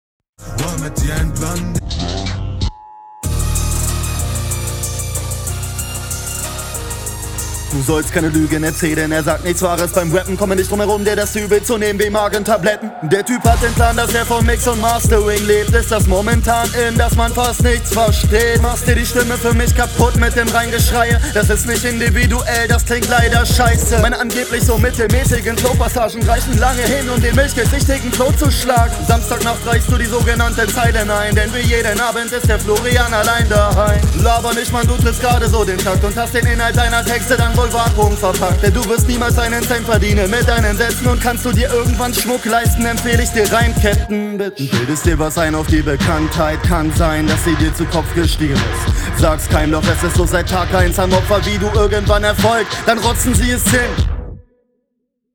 Autotune nicht so meins. Man merkt aber das der Beat nicht ganz deins ist.